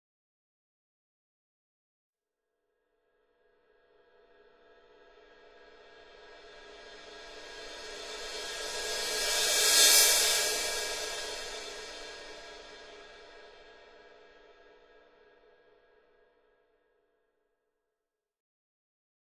Cymbal, Two, Crescendo, Type 4 - Scary